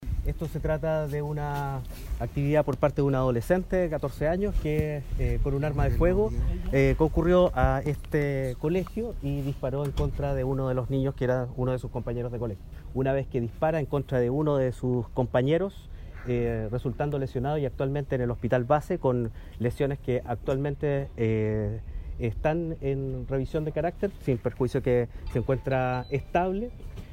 27-FISCAL-MIN-PUB.mp3